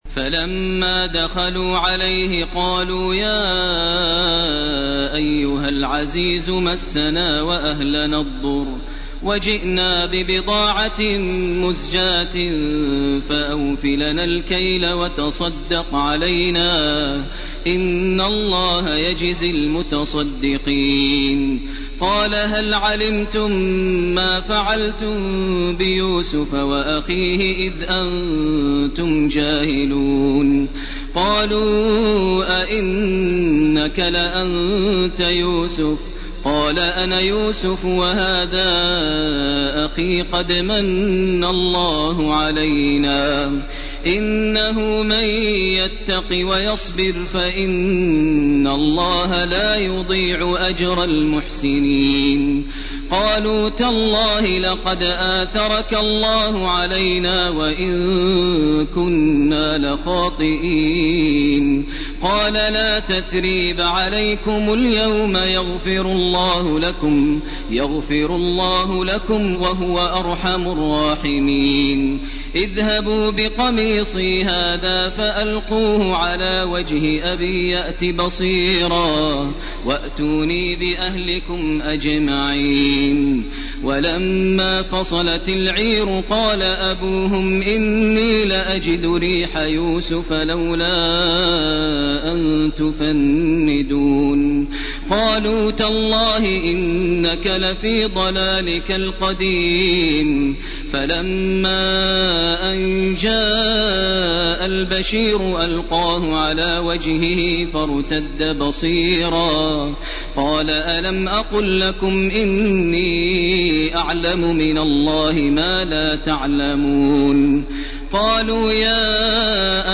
Surah Yusuf 88 to the most recent Surat Al-Raad 1-18 > Taraweh 1429 > Taraweeh - Maher Almuaiqly Recitations